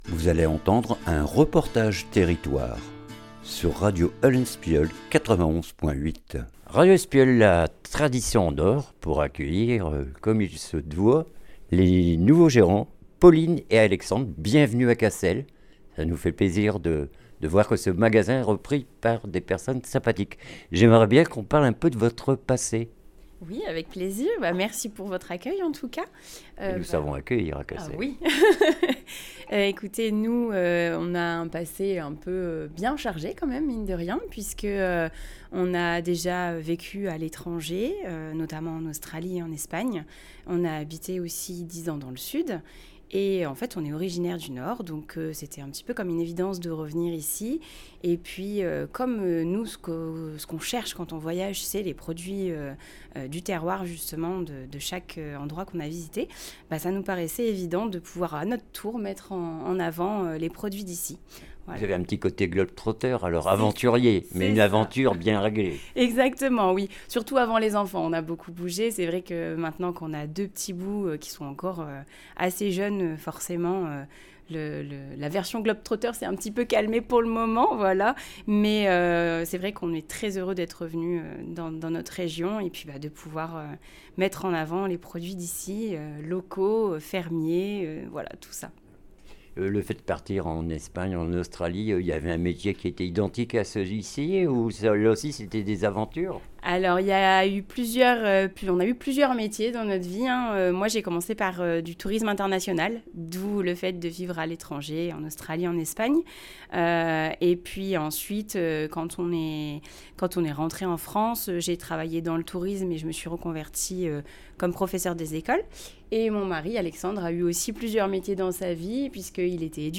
REPORTAGE TERRITOIRE TRADITIONS EN NORD 2026